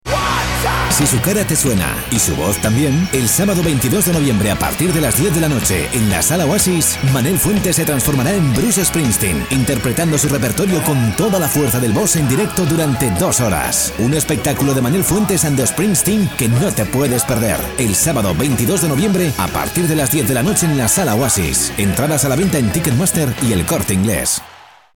Voz joven para cuñas, spots de TV, doblajes y presentaciones.
Sprechprobe: Werbung (Muttersprache):